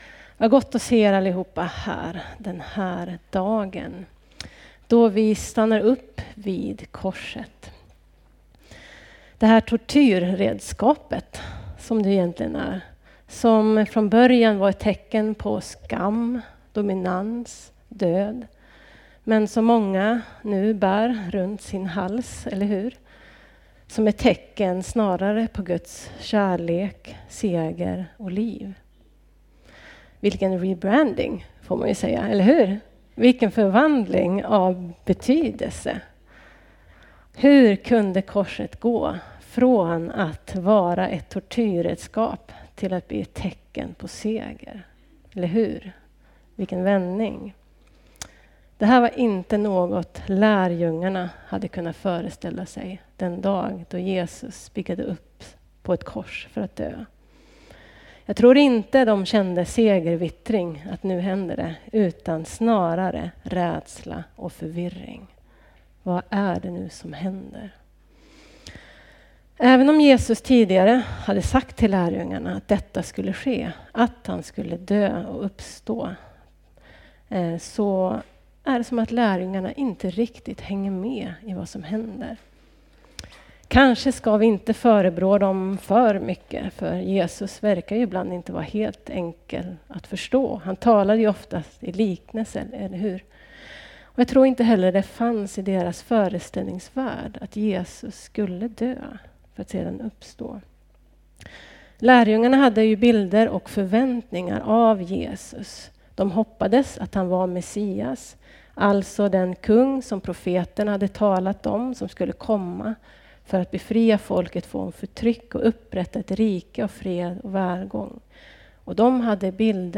Predikningar Elimkyrkan Gammelstad